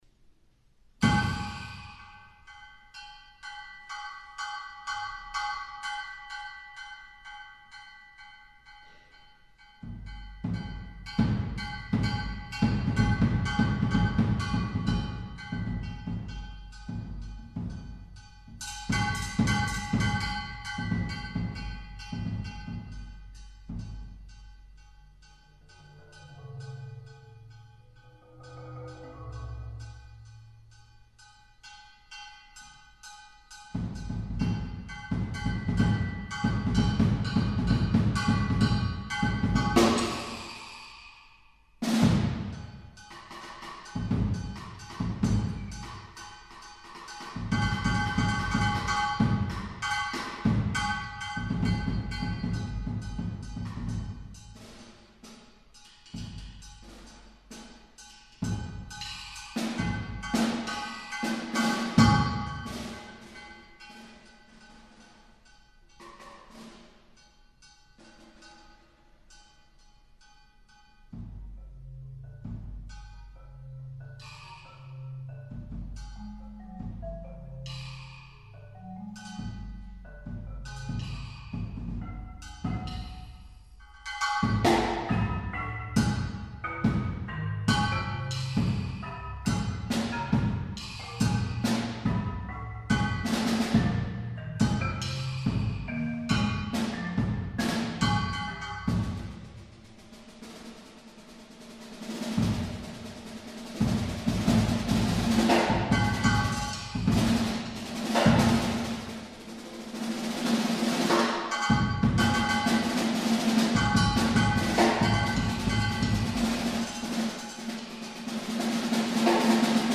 Genre: Multiple Percussion
Kick Drum
Snare Drum
Brake Drum
Vibraslap
Marimba (5-octave)